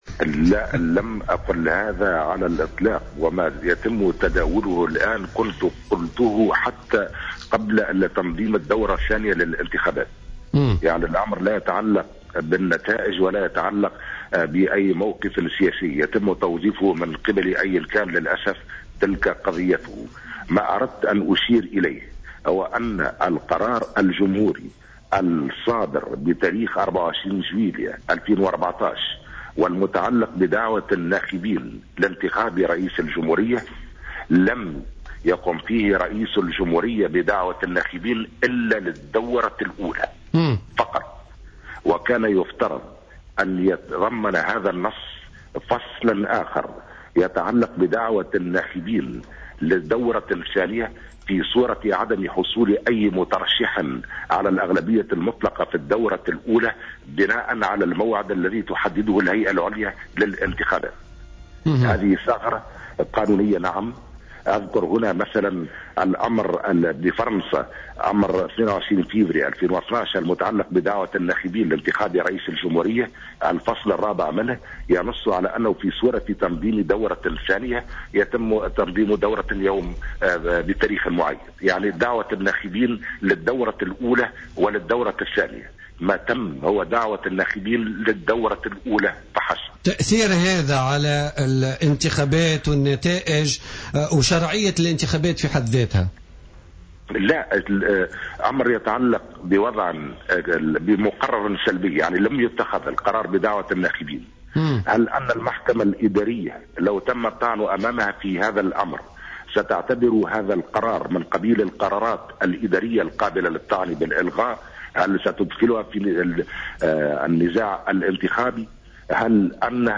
Intervenant sur les ondes de Jawhara FM dans le cadre de l’émission Politica du mardi 23 décembre 2014, Kais Saied, expert en droit constitutionnel, a démenti les rumeurs selon lesquelles il aurait prétendu l’invalidité des élections.